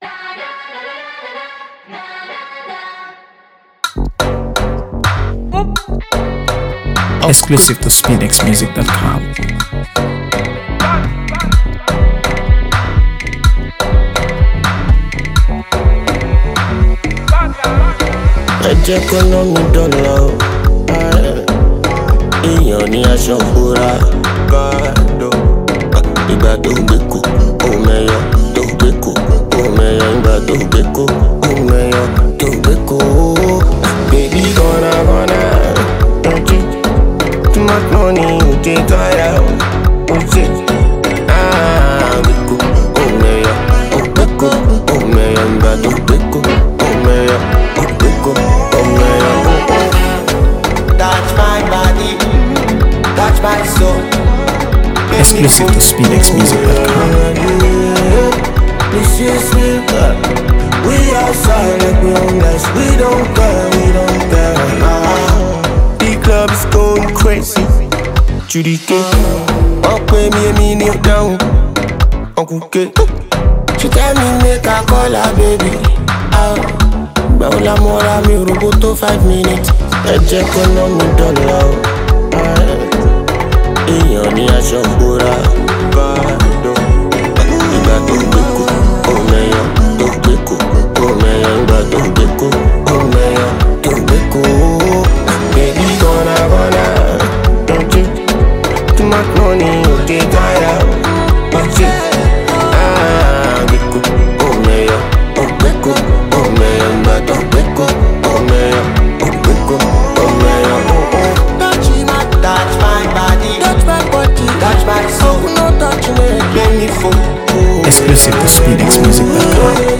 AfroBeats | AfroBeats songs
Packed with rhythmic grooves and catchy hooks